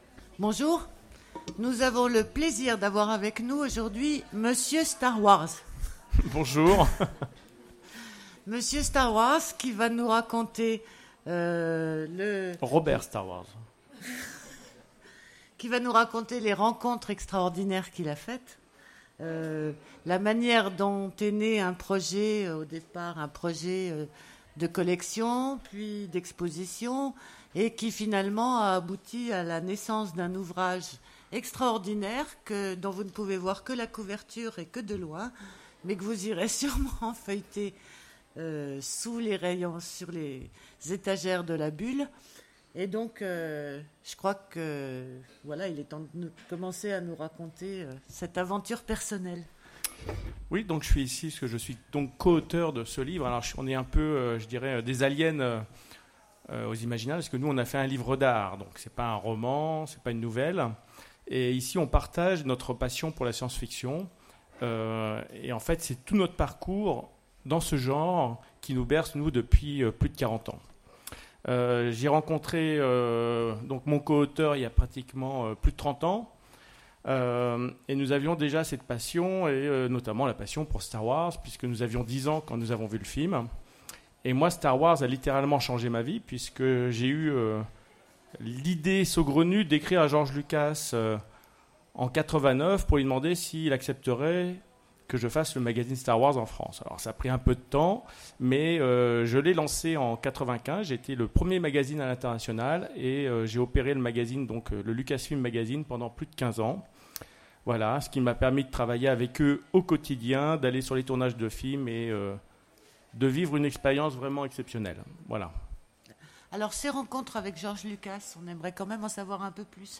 Imaginales 2016 : Conférence De Star Wars à Matrix…